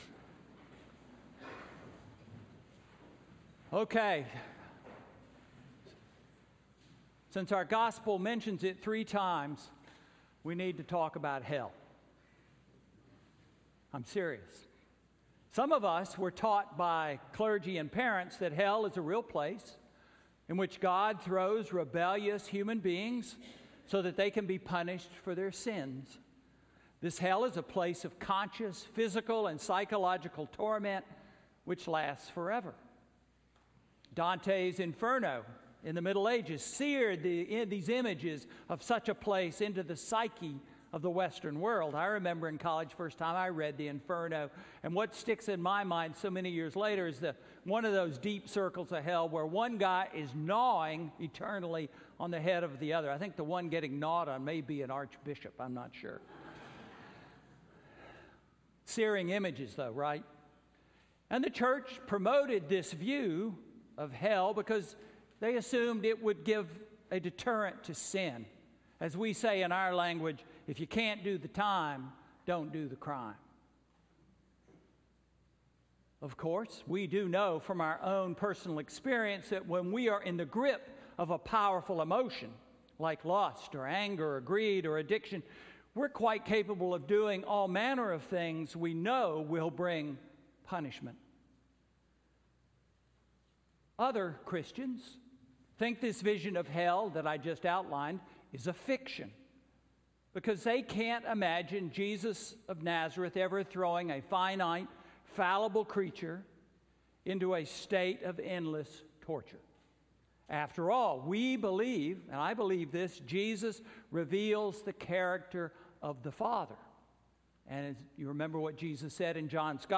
Sermon–Where is hell? September 30, 2018